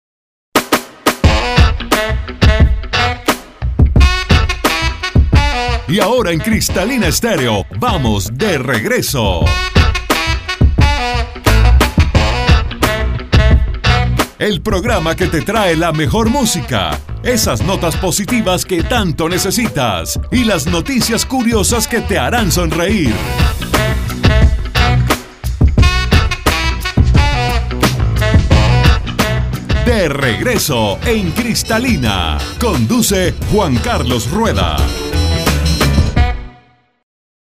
Aquí encontrará la música variada, alegre y siempre arriba que hace más ligero el viaje; entrevistas y notas entretenidas sobre música, cultura, salud, cine y mucho más… todo en un tono fresco, divertido e informal, como quien charla con amigos.